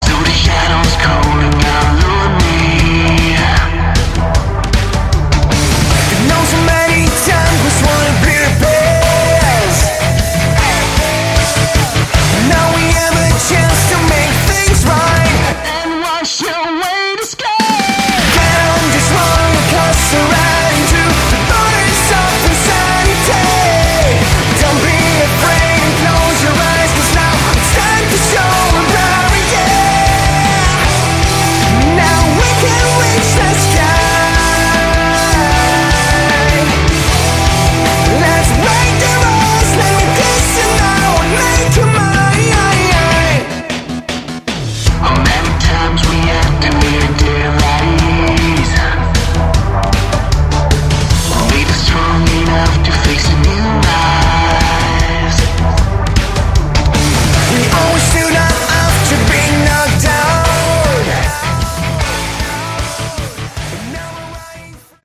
Category: Sleaze Glam
vocals
bass
drums
guitar